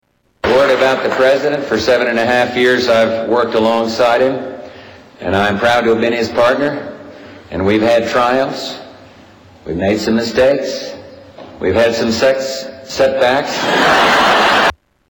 George Bush SR - Sex From Freudian Slips - Live on the News !
Tags: Media Freudian Slips News Newscasters Funny